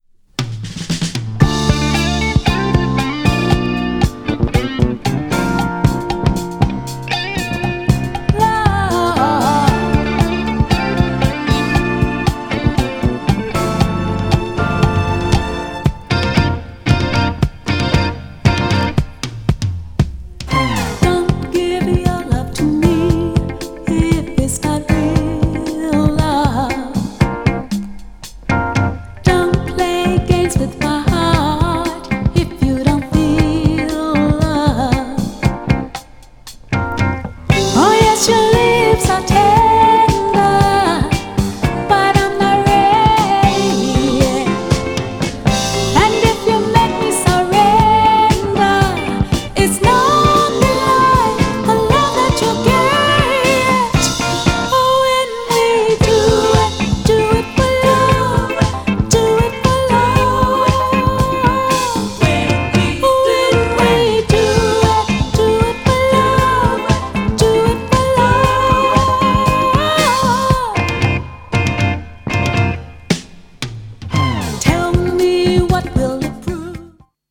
パーカッシブなTRACKにコケティッシュなボーカルが絶妙!! 後半のドラマティックな展開も最高。"
GENRE Dance Classic
BPM 116〜120BPM